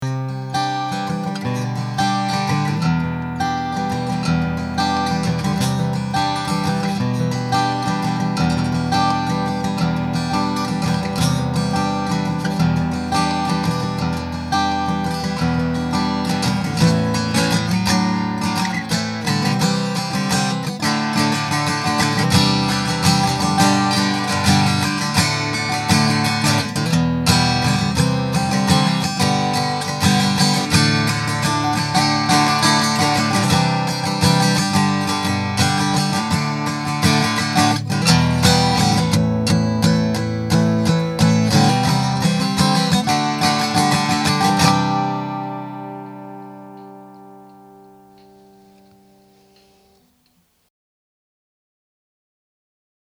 I also recorded a couple of clips with the guitar miked as well to give you an idea of the natural sound the guitar makes:
The mic was placed about a foot away from the guitar to allow its tones to develop.
mic.mp3